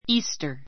Easter íːstə r イ ー ス タ 固有名詞 復活祭, イースター ⦣ キリストの復活を記念する祭で, 3月21日（春分）以降の満月の次の日曜日 （ Easter Day または Easter Sunday ）.